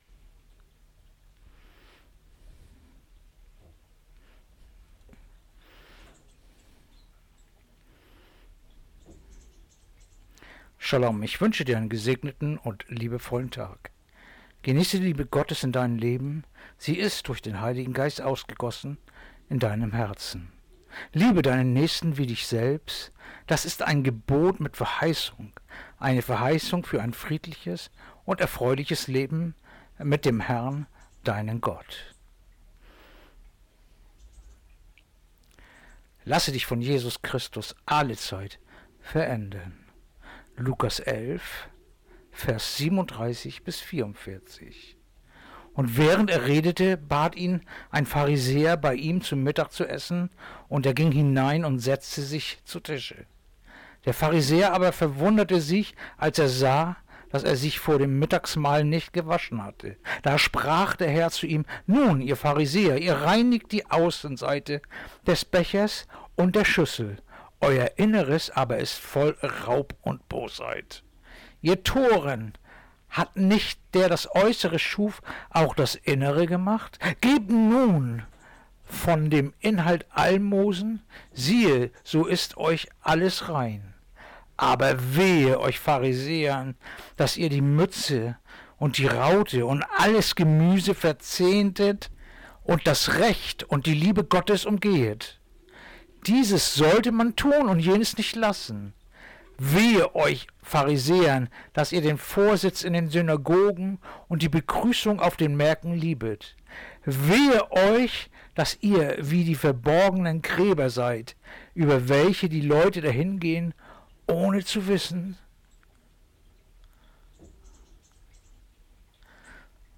Andacht-vom-19.-Maerz-Lukas-11-37-44